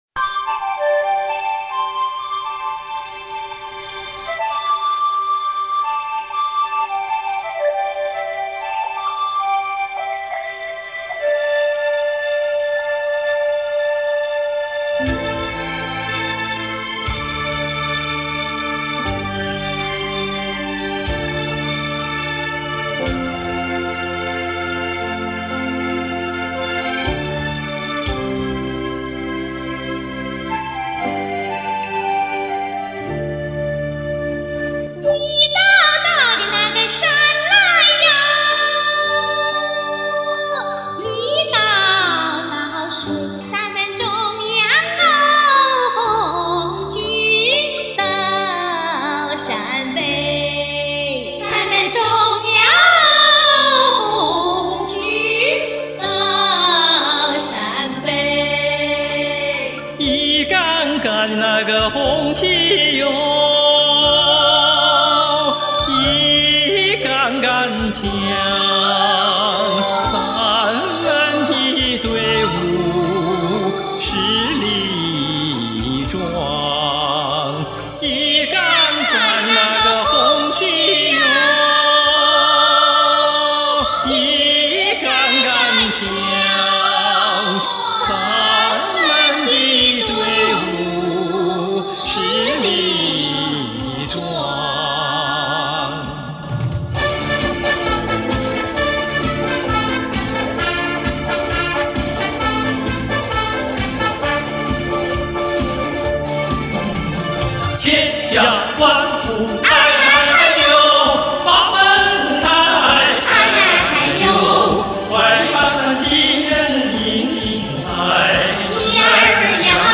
其中有領唱，多軌合唱，與和聲伴唱等形式。
陝北民歌